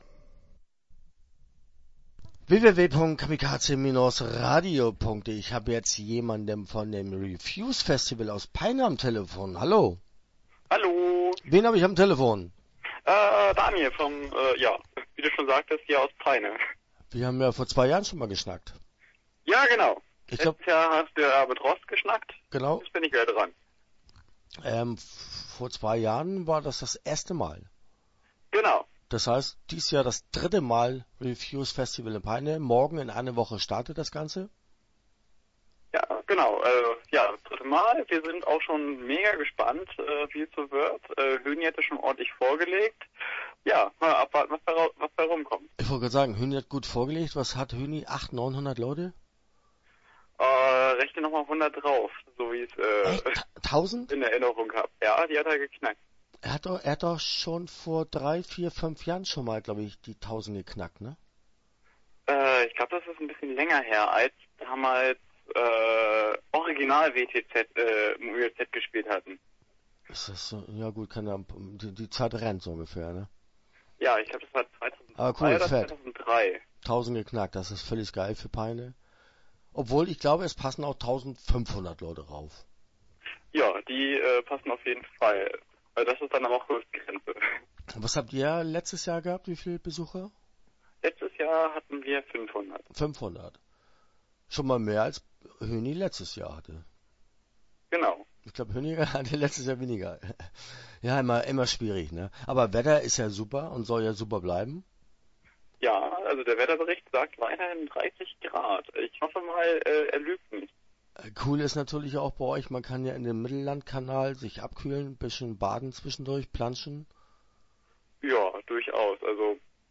Start » Interviews » Refuse-Festival Peine